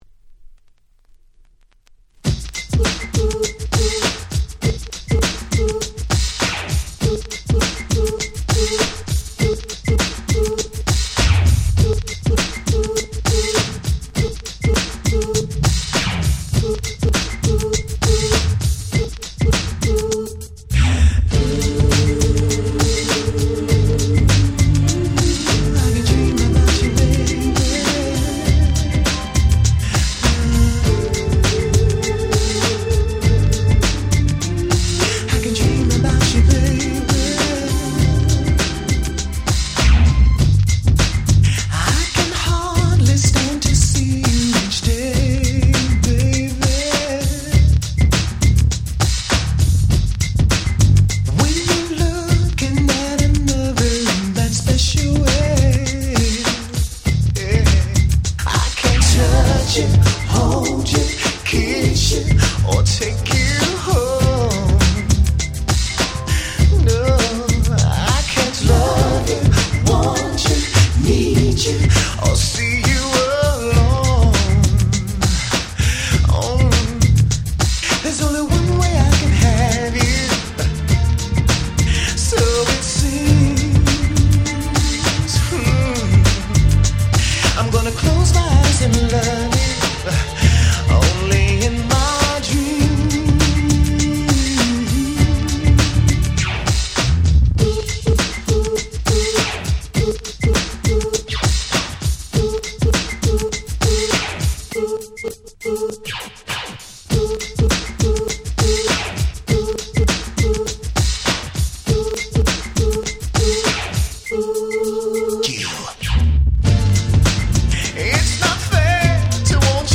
89' Nice R&B / Ground Beat / New Jack Swing !!